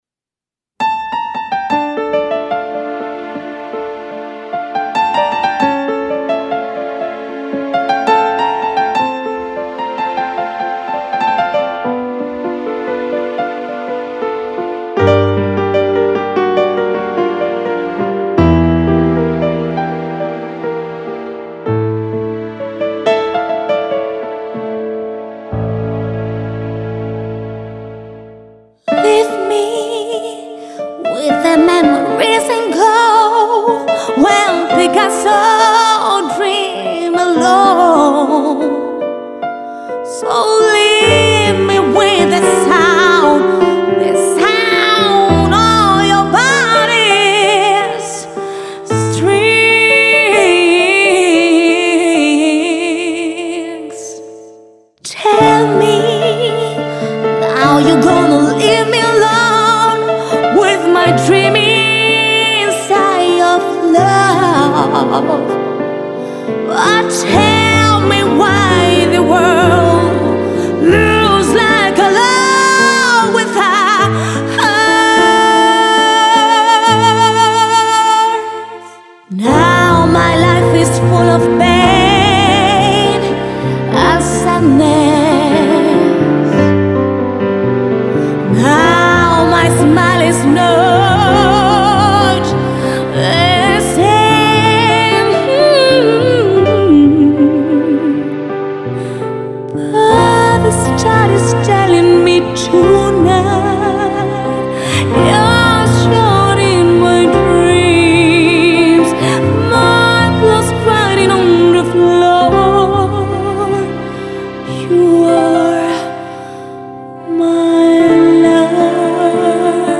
soul, el blues y el jazz